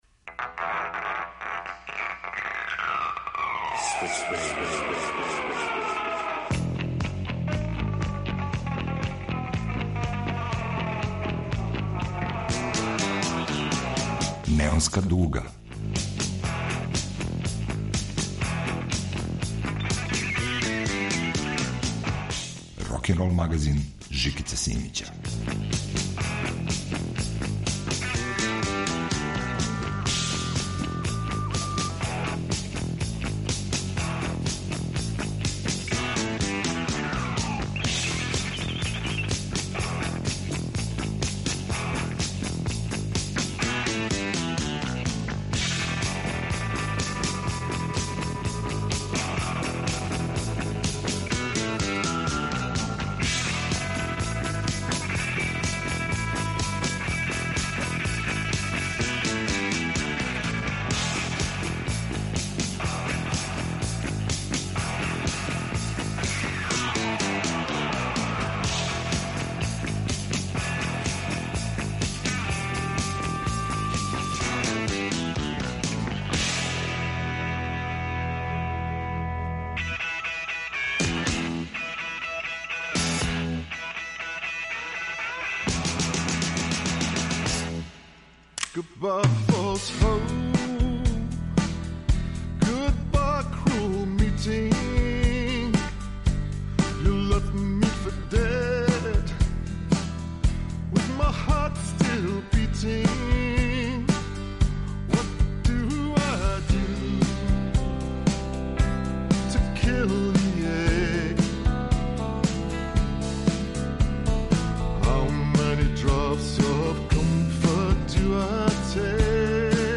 Neonska duga - rokenrol magazin Žikice Simića, 9. 6. 2024.